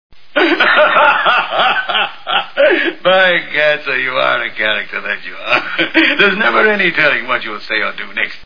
The Maltese Falcon Movie Sound Bites